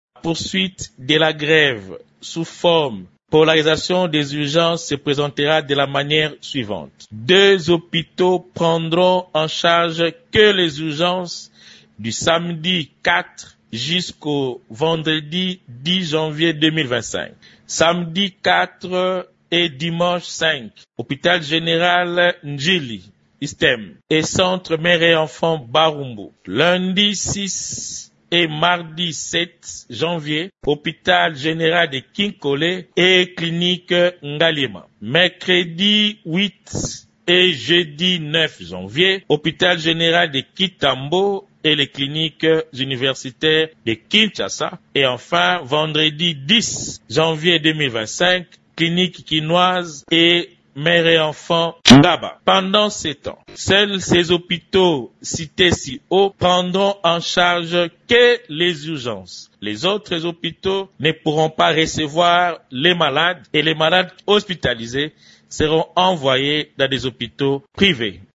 Dans un entretien accordé à Radio Okapi